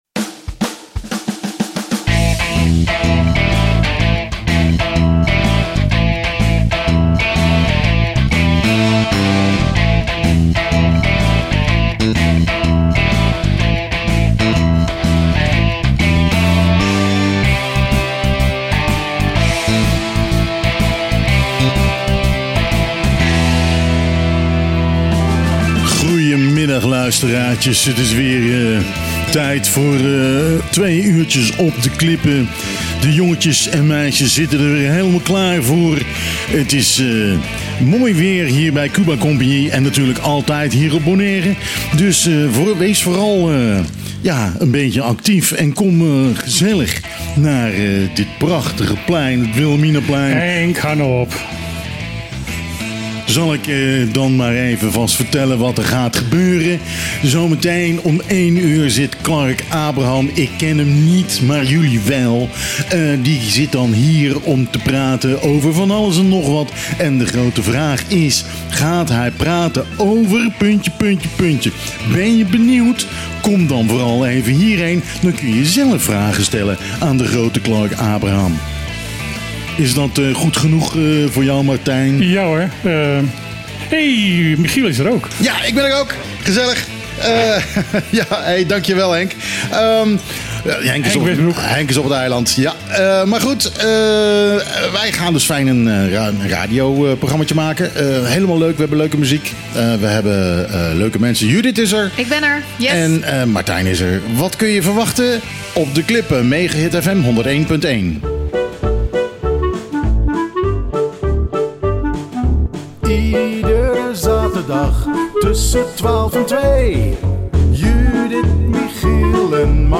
De integrale opname van de uitzending van het radioprogramma Op de Klippen (zonder muziek).